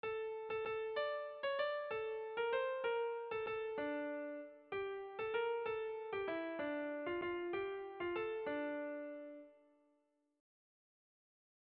Bertso melodies - View details   To know more about this section
Erromantzea
AB